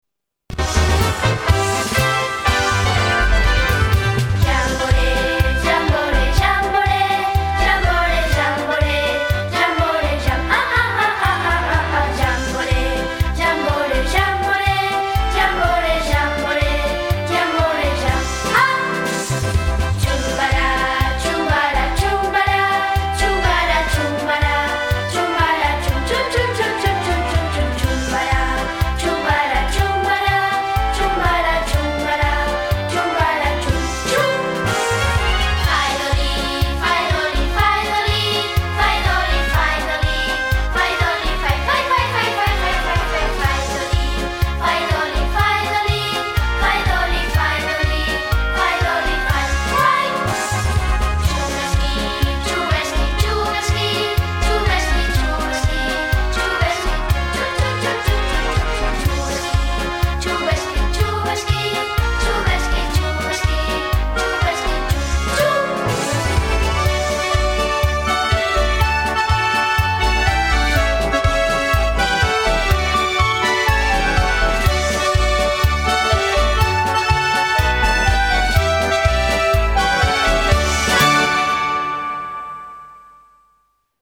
*  Jamboree és una cançó tradicional danesa.